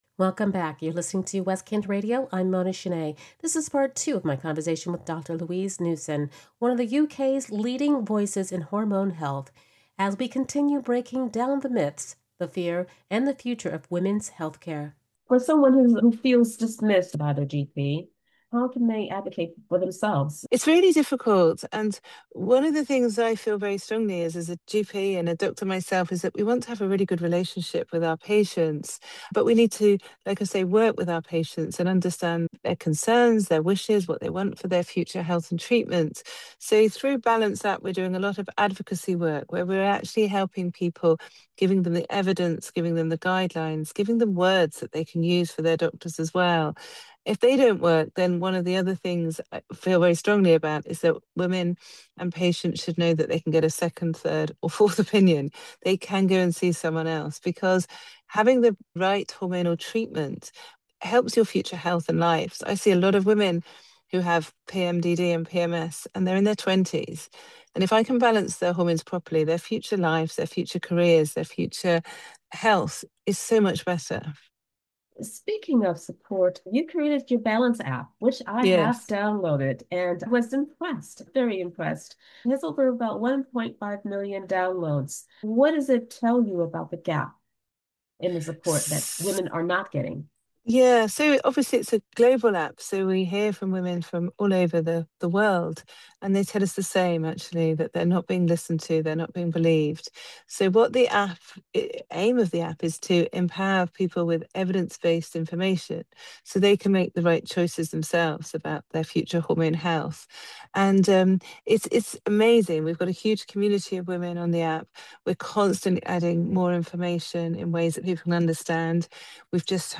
Here's the full interview in 2 parts